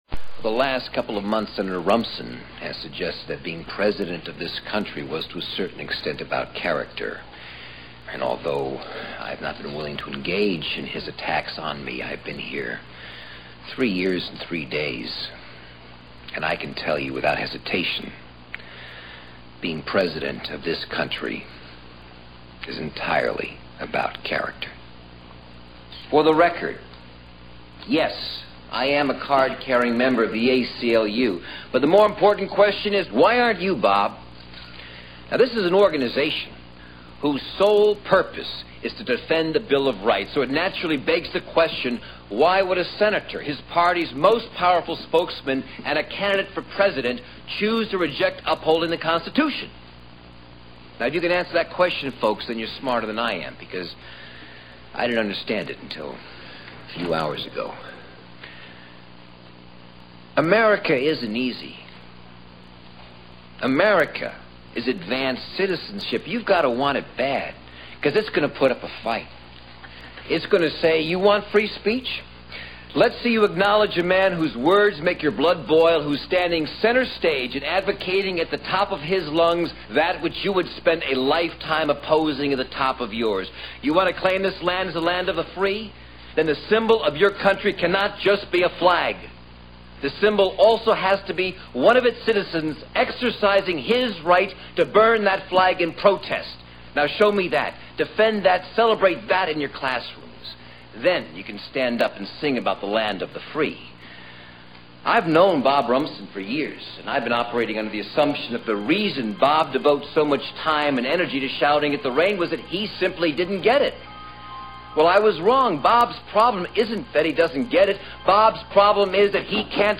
President Andrew Shepherd addresses to the Press about Senator Bob Rumson and the Crime Bill